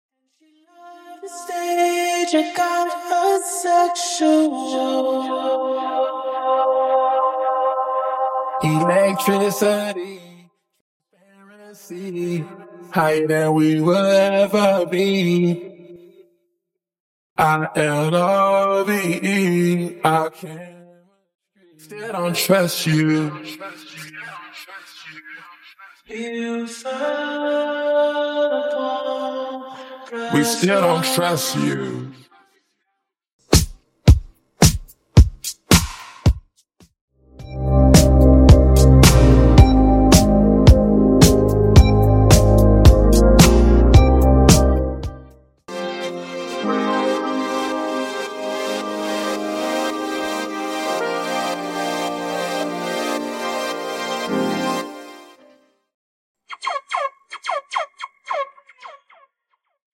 808 Stem
Cosmic Synths Stem
Percussion & Drums Stem
Striped Pads Stem